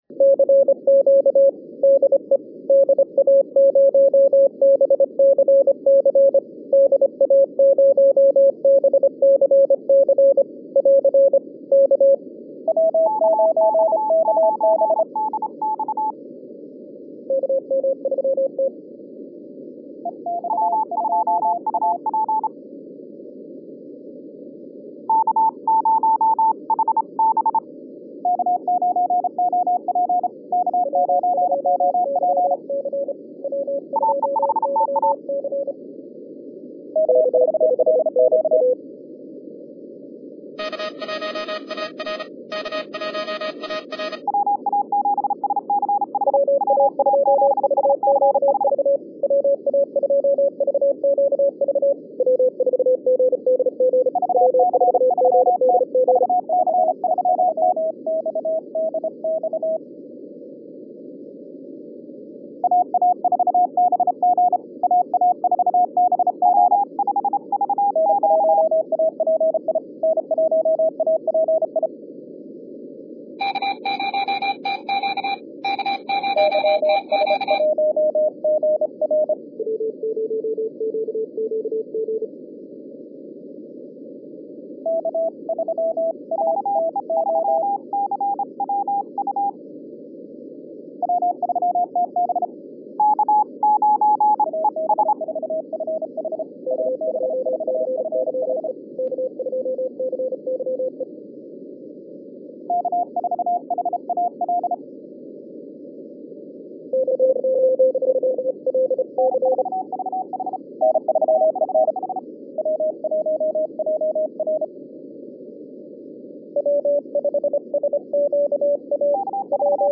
Unsere bewährten Aktivitäten am Stand – ob die BCC Challenge mit dem CW-PileUp-Wettbewerb oder die HAM Rallye für den Nachwuchs – fanden erneut Anklang.
2012 Tape BCC Challenge Saturday: